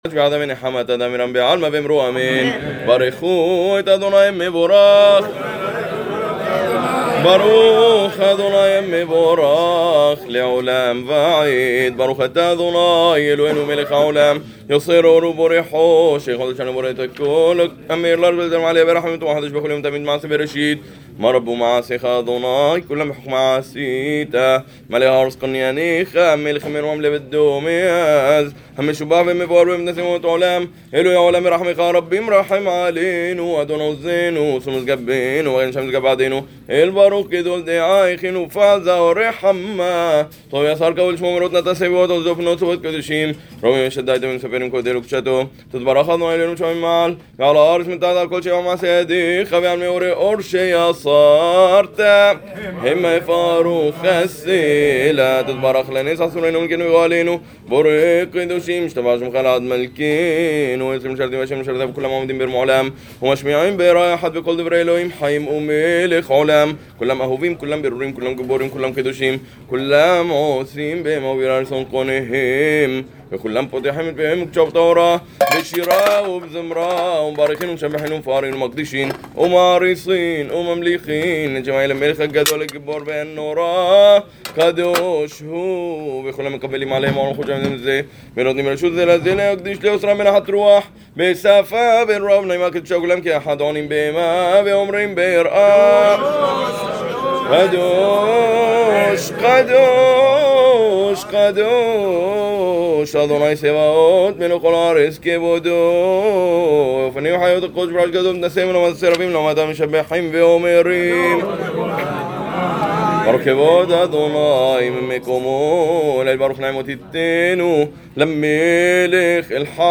6. SHACHARIT (De Yotzer Or hasta Ga-al Israel, dicha por un Hazan).